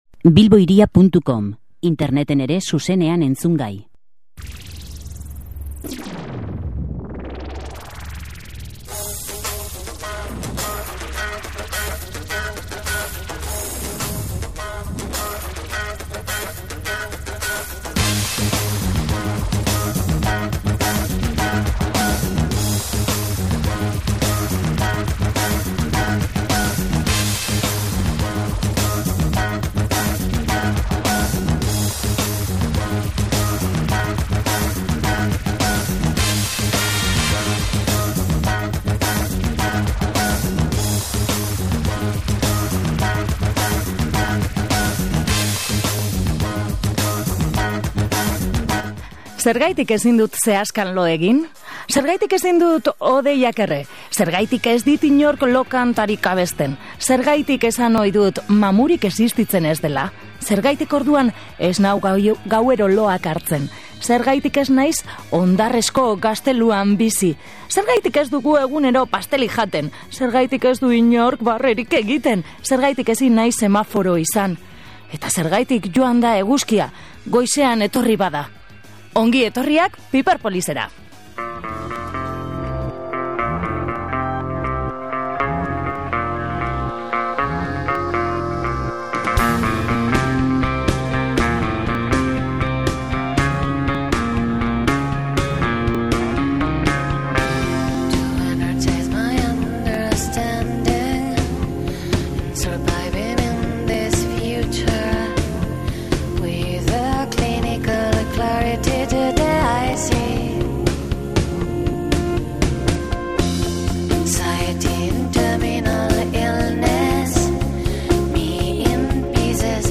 Piperpolis:Ruper Ordorikarekin solasean | Bilbo Hiria irratia